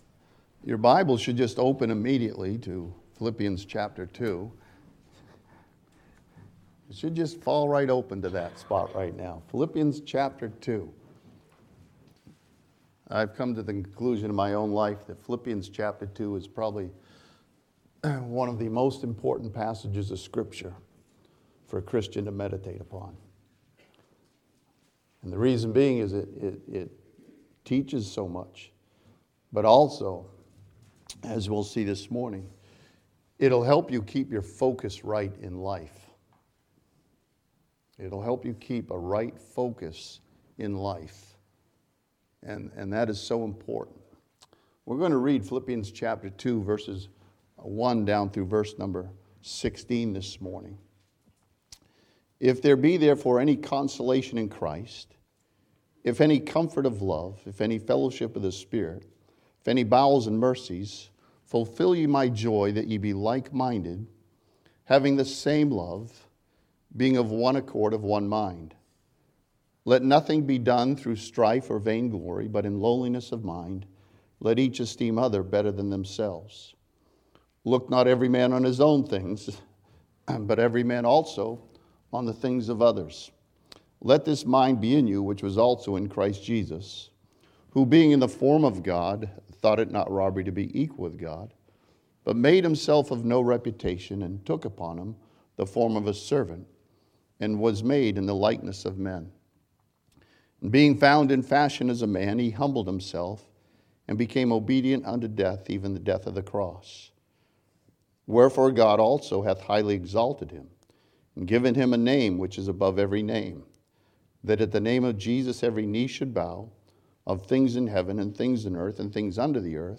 This sermon from Philippians chapter 2 challenges believers to have the right focus as they go through life.